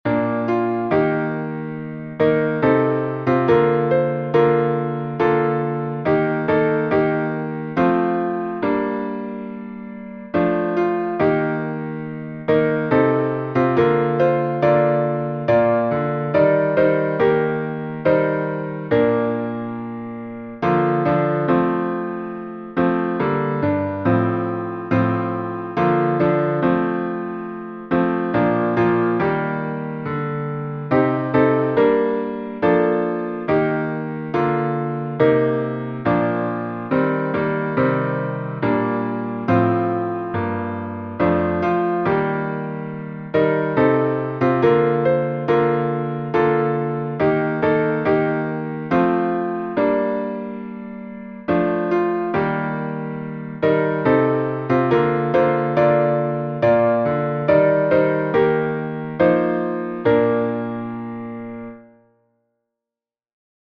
Downloads Áudio Áudio cantado (MP3) Áudio instrumental (MP3) Áudio intrumental (MIDI) Partitura Partitura 4 vozes (PDF) Cifra Cifra (PDF) Cifra editável (Chord Pro) Mais opções Página de downloads
salmo_70B_instrumental.mp3